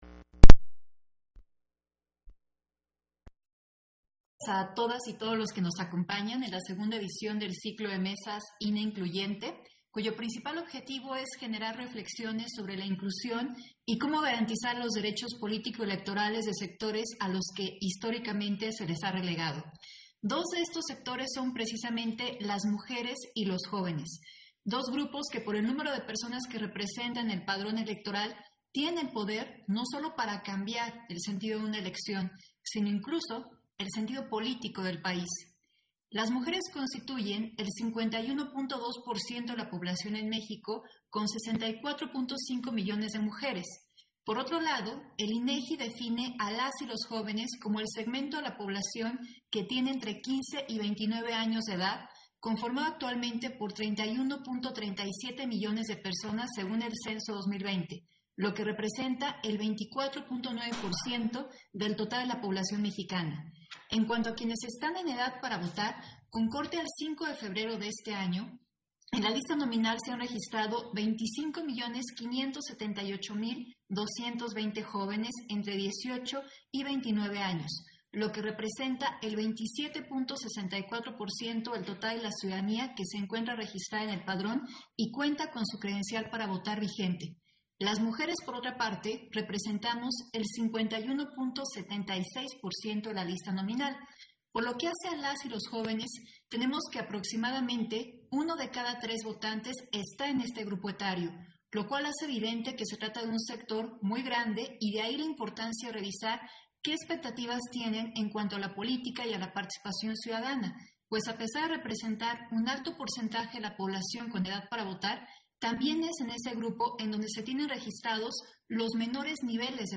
Intervención de Dania Ravel, durante la videoconferencia: El uso de redes sociales por parte de las juventudes y las mujeres candidatas en procesos electorales